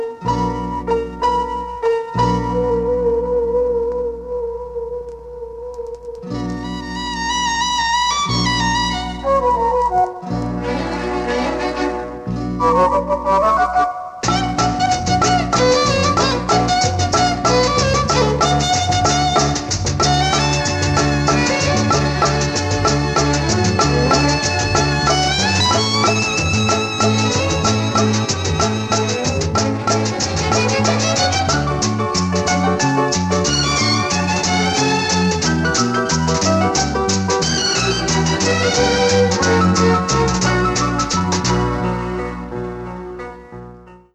The solo voilin plays for a good 5-6 seconds.